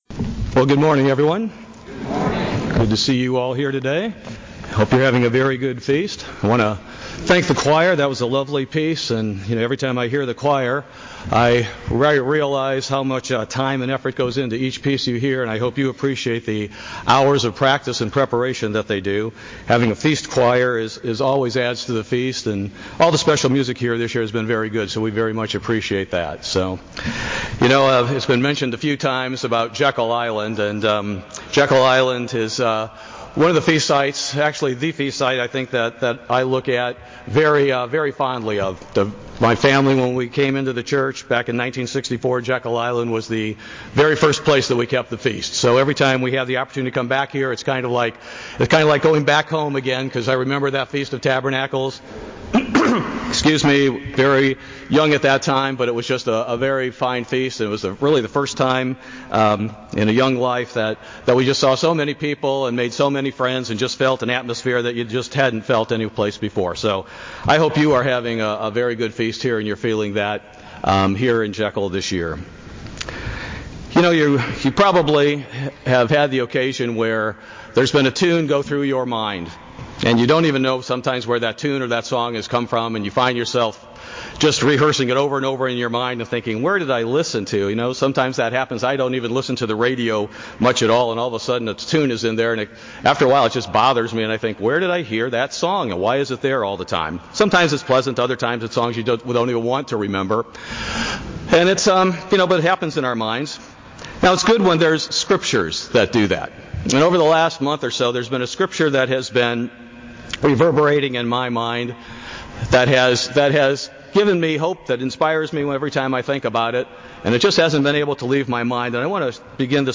This sermon was given at the Jekyll Island, Georgia 2018 Feast site.